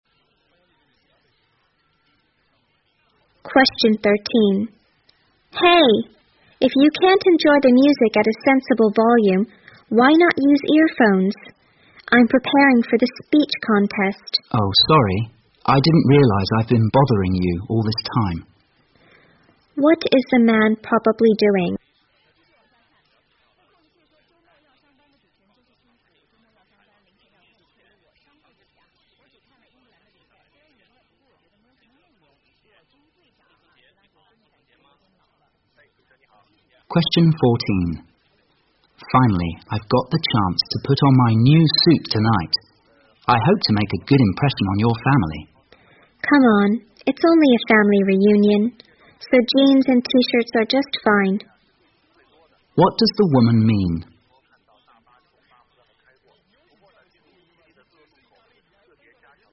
在线英语听力室017的听力文件下载,英语四级听力-短对话-在线英语听力室